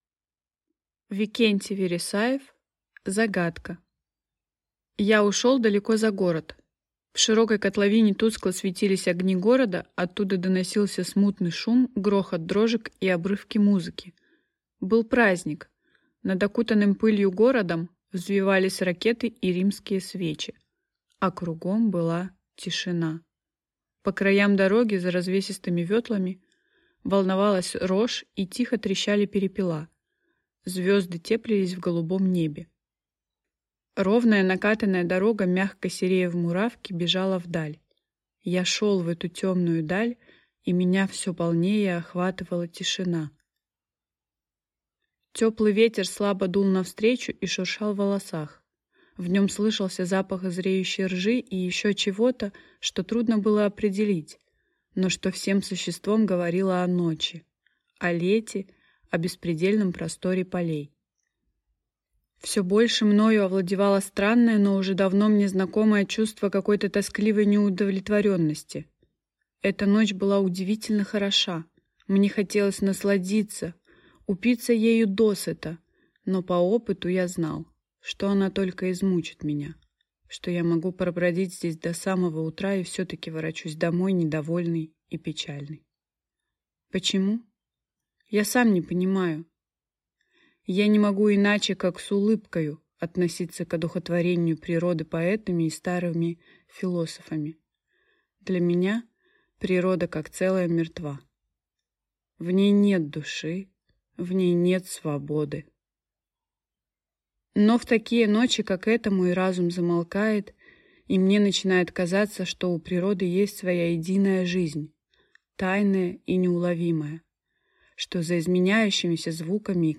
Аудиокнига Загадка | Библиотека аудиокниг